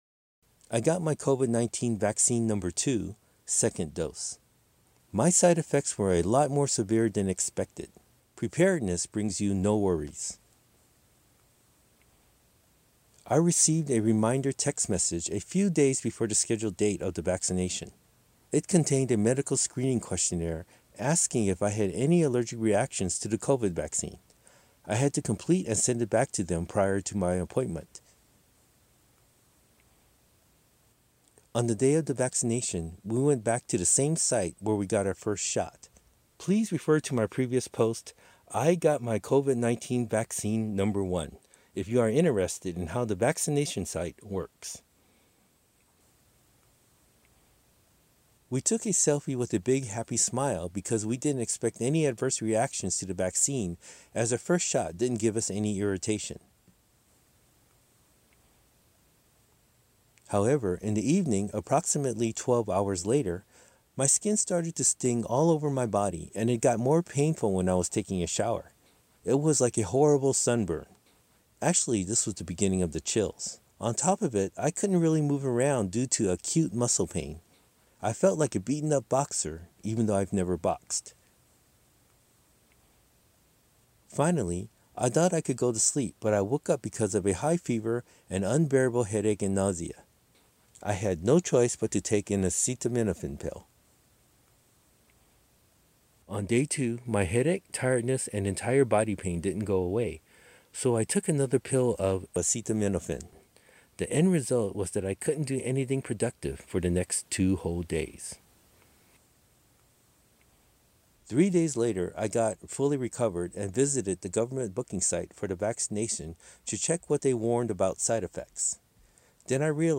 英訳全文をネイティブの発音で聴けるオーディオ付き
ネイティブの発音オーディオ：